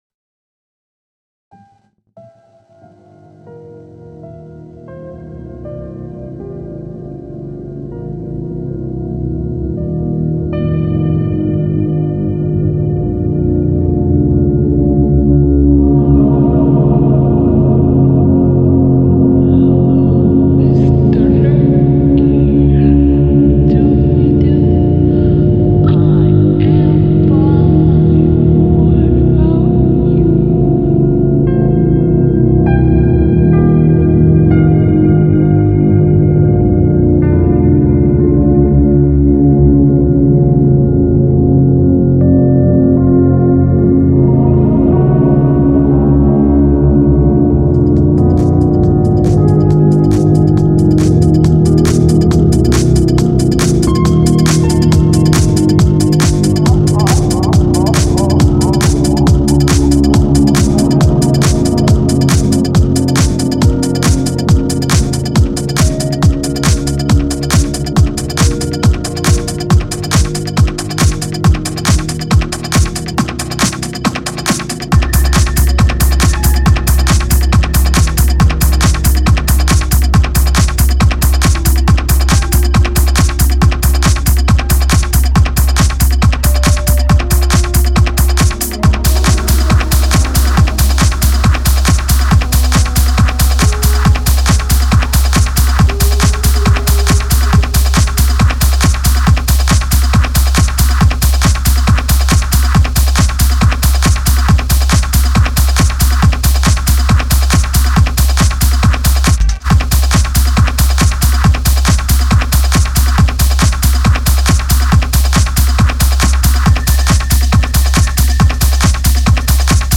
techno set